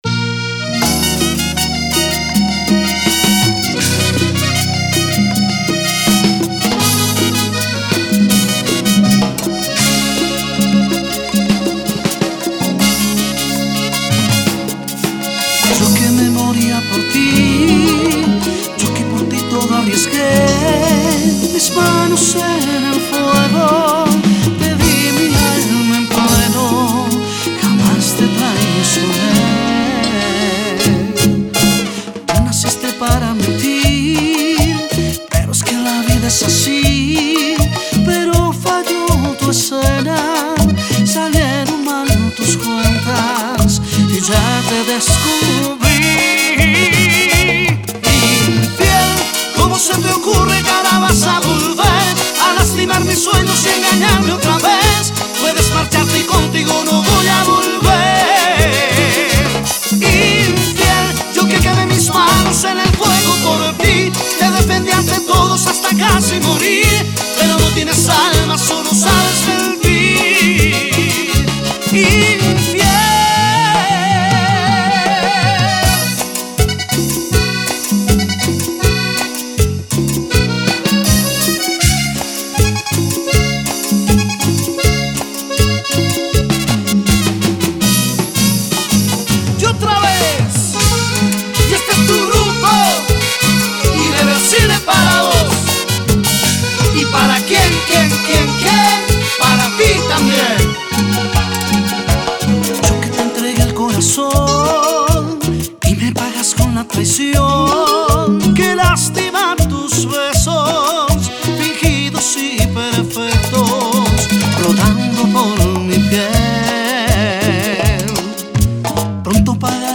Genre Cumbia Latina